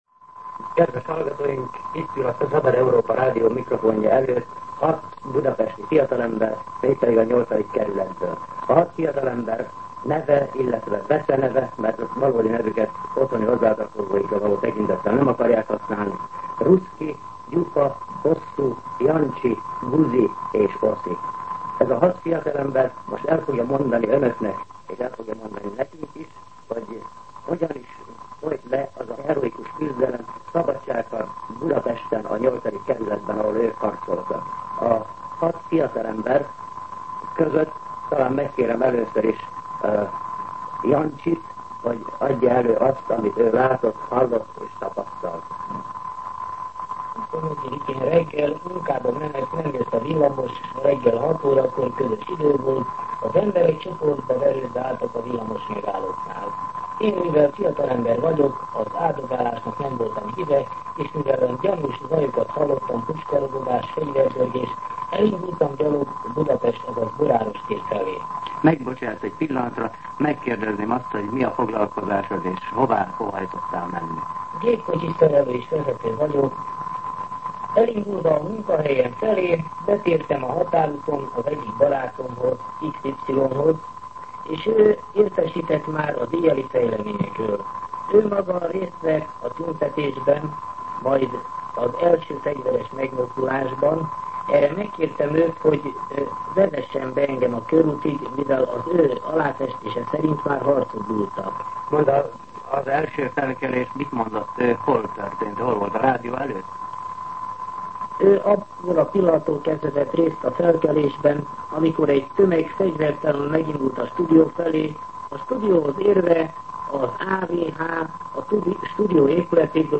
Szemtanúk beszámolói